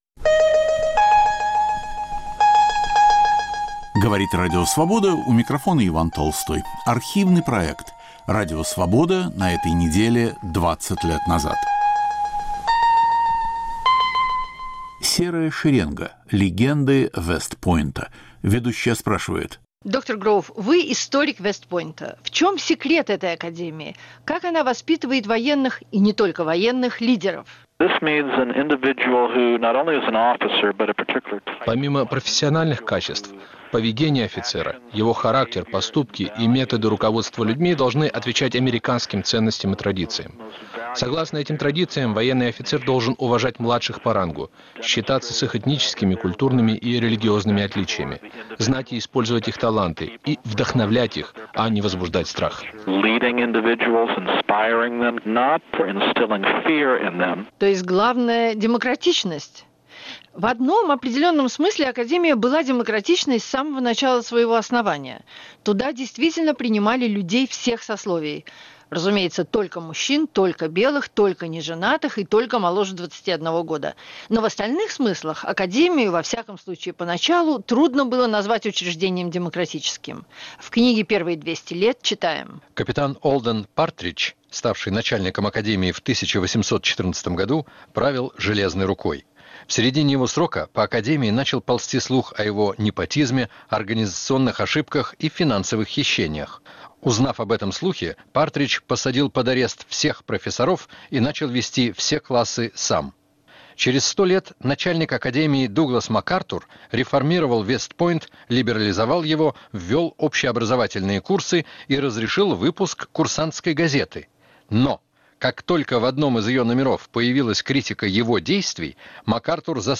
Экологическая программа. Вредны ли генно-модифицированные продукты?